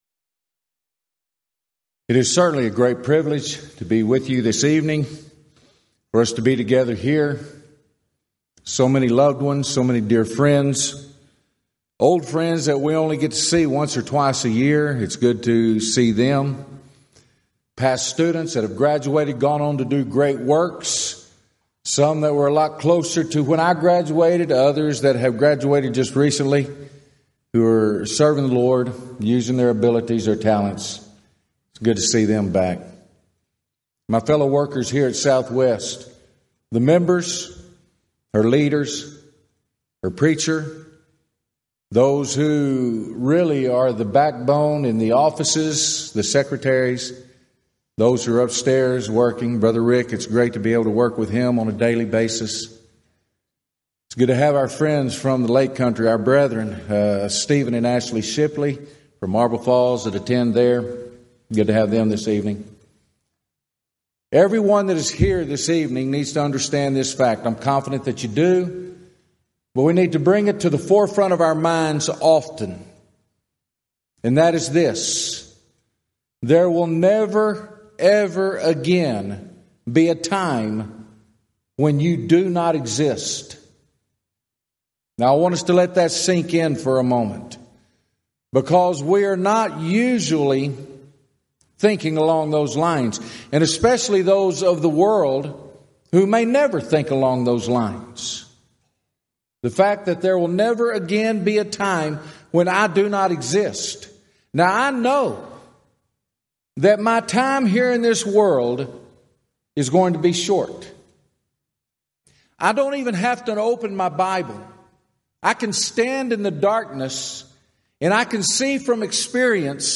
Event: 34th Annual Southwest Lectures
lecture